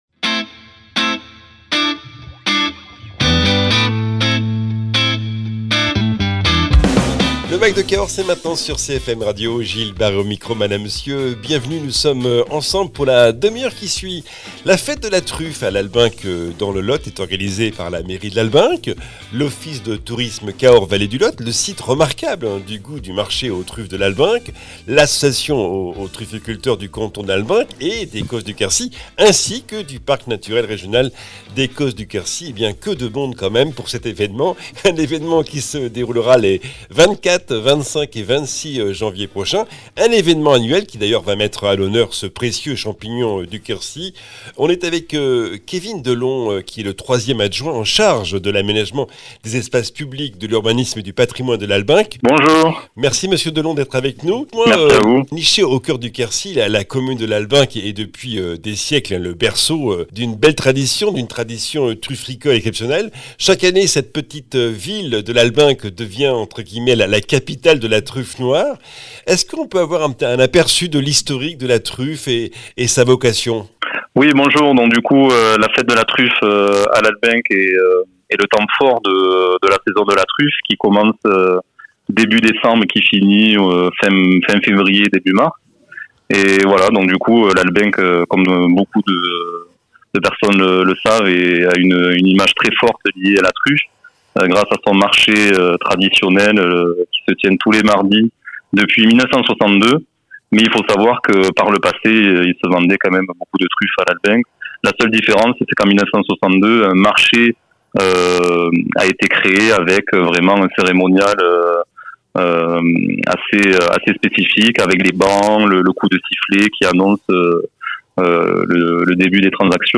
Invité(s) : Kevin Delon, 3ème adjoint en charge de l’aménagement des espaces publics, de l’urbanisme et du patrimoine de Lalbenque